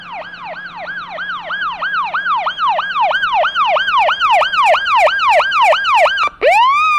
Polis Sireni v3